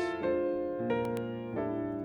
I have a problem with clicks/pops from my analog recording equipment.
) you can see/hear two of them.
They are very short (~10ms) so I thought I would try simply silencing them.